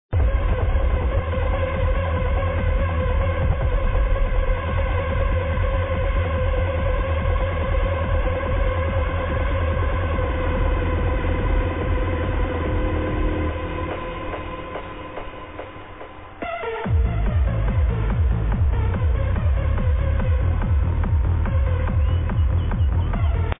Powered by: Trance Music & vBulletin Forums